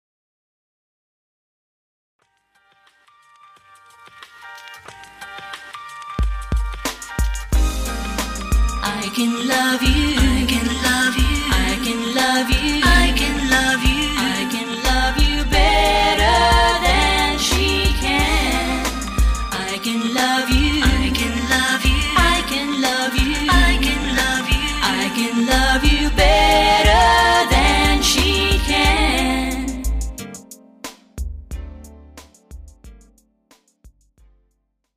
Pop , R&B , Soul